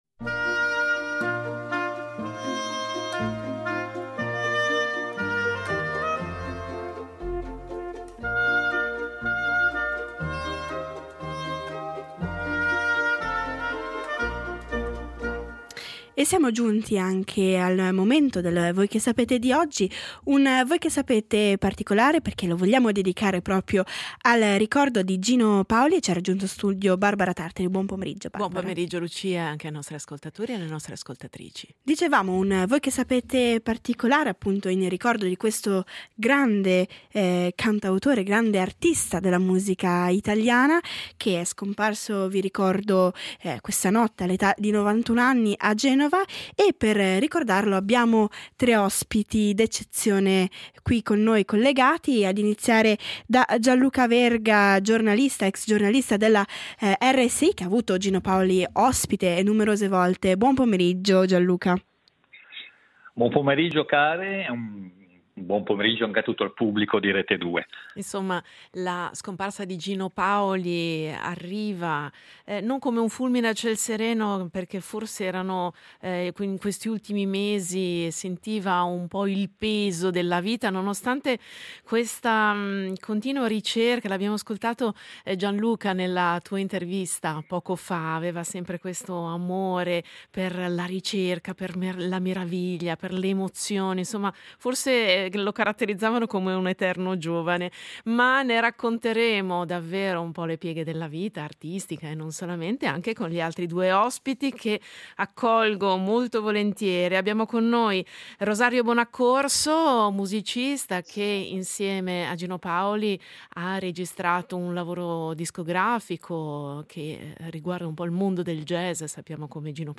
A Voi Che Sapete vogliamo dedicargli uno spazio di discussione musicale per ripercorrerne il cammino umano e artistico.